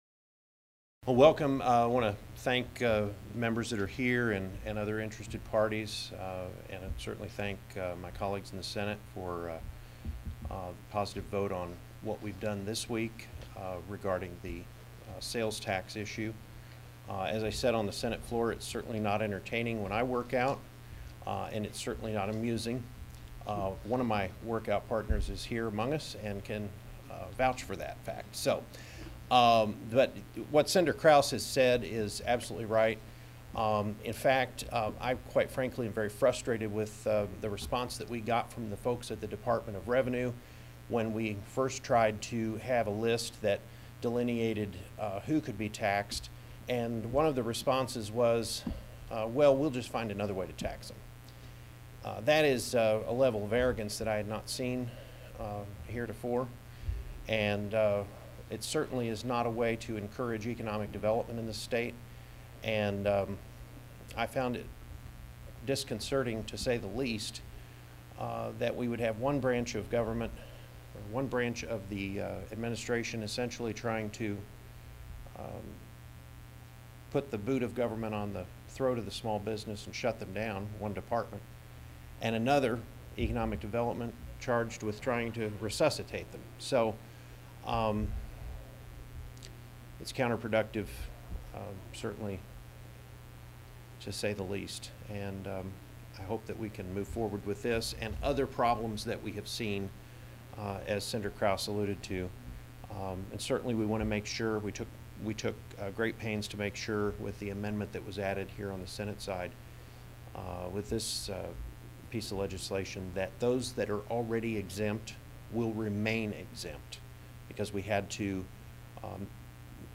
The cuts below are taken from a press conference held on April 9, 2014.
Senator Dixon and colleagues address members of the media regarding practices by the Missouri Department of Revenue relating to “notification by audit.” (2:24)